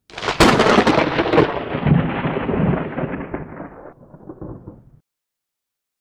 Soundeffekte